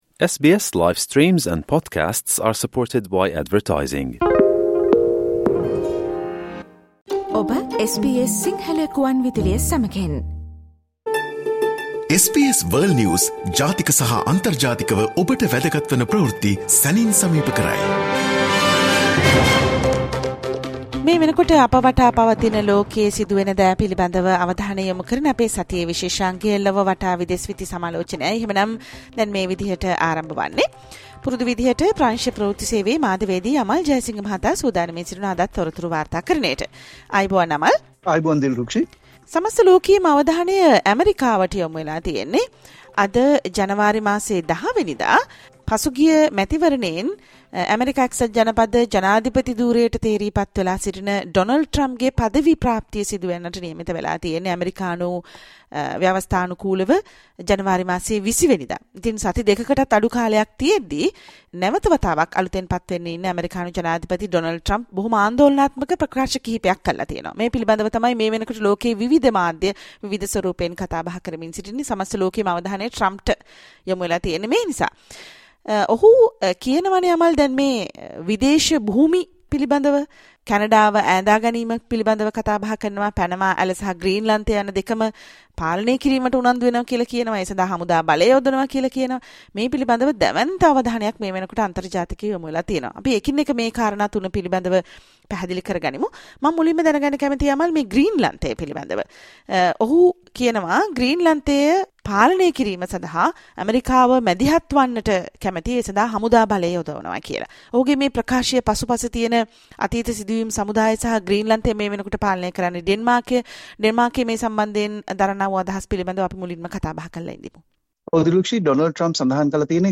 world news highlights